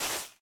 Minecraft Version Minecraft Version 1.21.5 Latest Release | Latest Snapshot 1.21.5 / assets / minecraft / sounds / block / suspicious_sand / place3.ogg Compare With Compare With Latest Release | Latest Snapshot